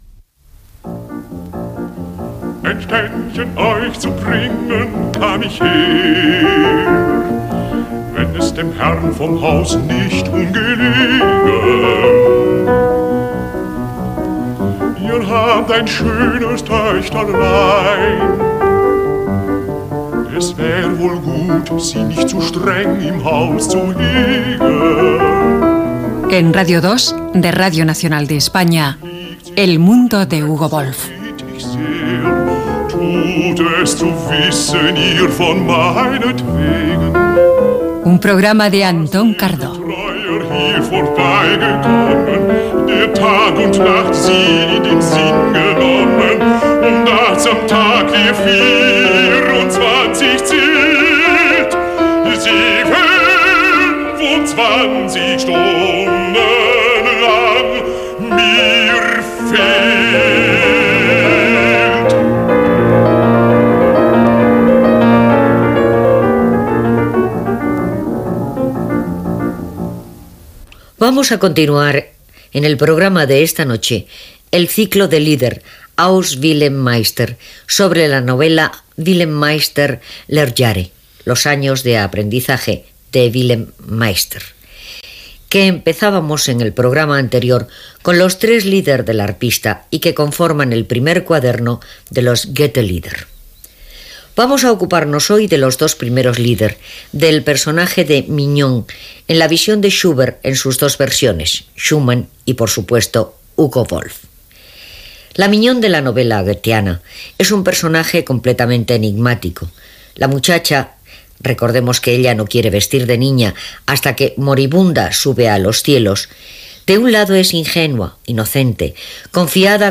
Careta del programa, espai dedicat al compositor Hugo Wolf.
Musical